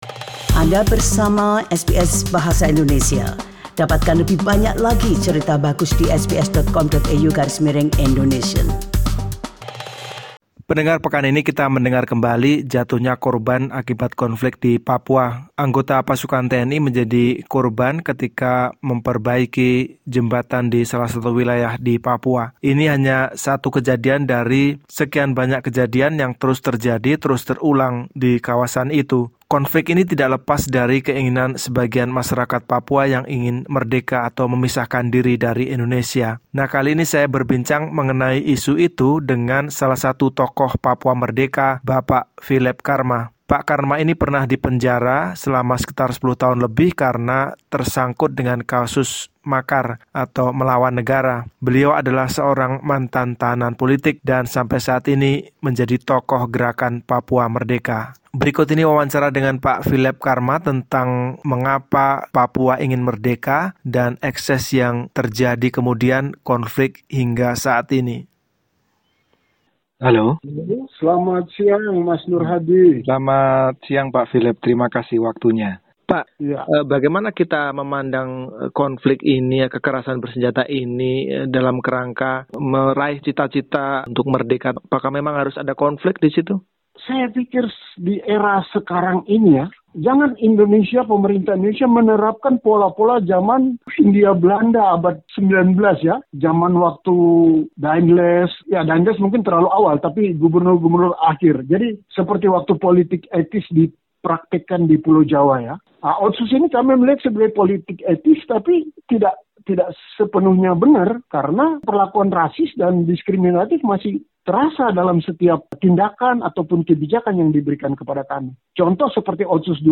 Tokoh Papua Merdeka, Filep Karma akan merefleksikan kembali cita-cita Papua Merdeka itu dalam wawancara berikut ini.